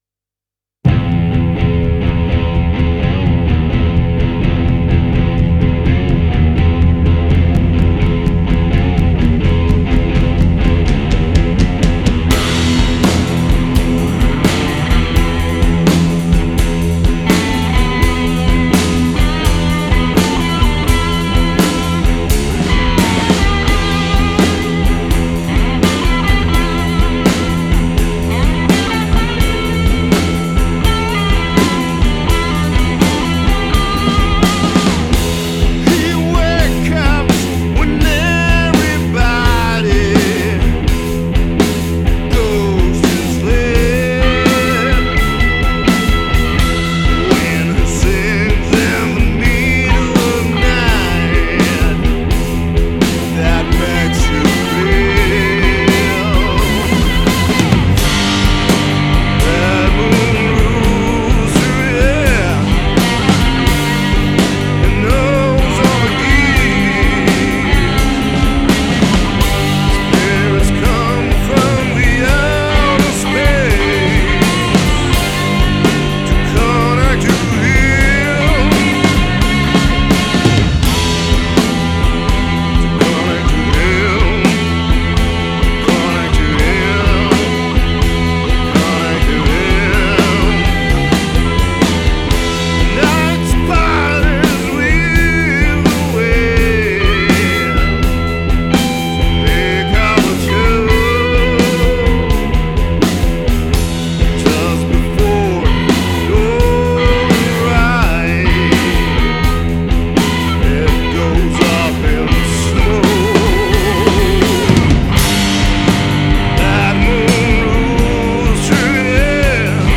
c’est la formation du duo batterie guitare